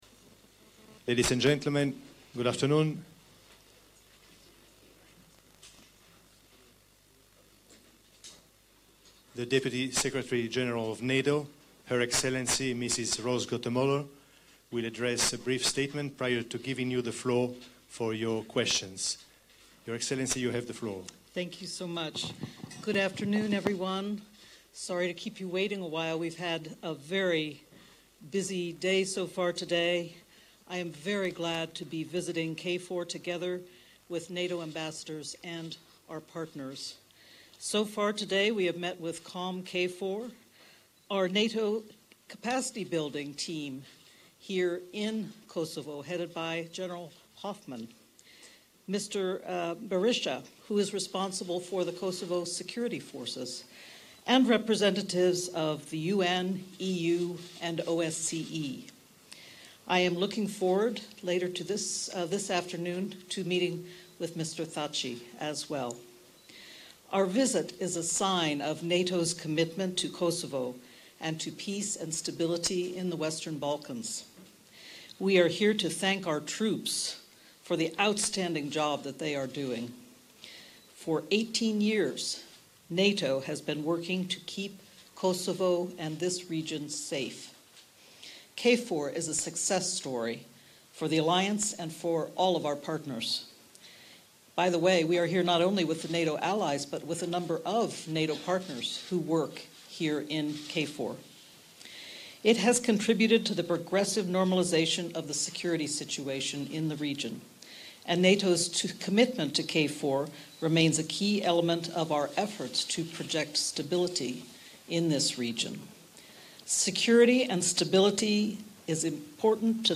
Press conference by NATO Deputy Secretary General Rose Gottemoeller at NATO HQ KFOR